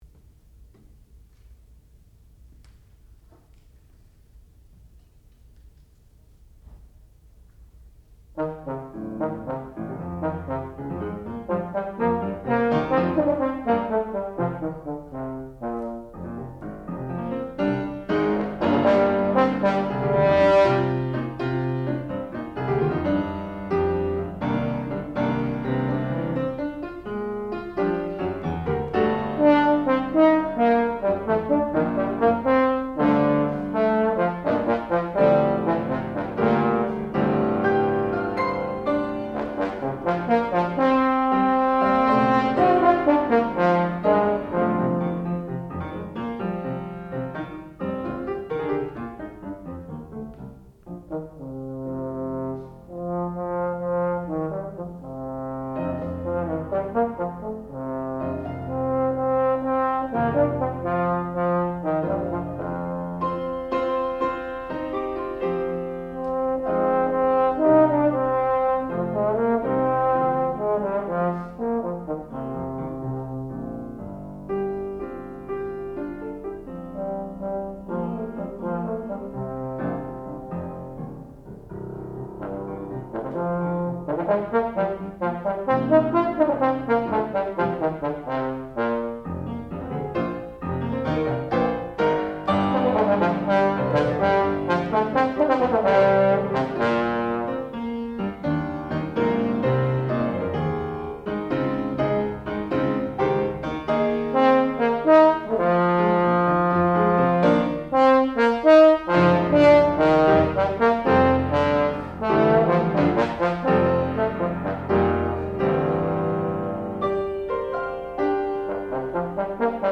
classical music
Master Recital
bass trombone